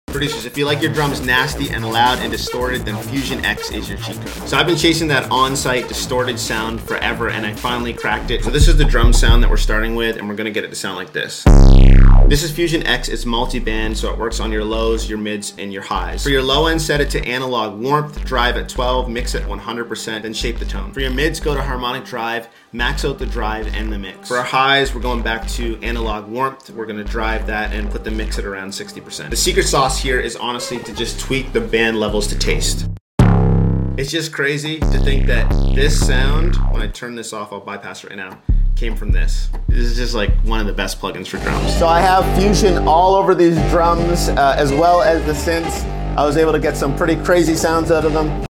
FUSION X is the perfect effect plugin for distorted 808s & drums